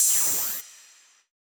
Index of /musicradar/ultimate-hihat-samples/Hits/ElectroHat C
UHH_ElectroHatC_Hit-26.wav